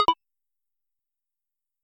SFX_UI_CloseMenu.mp3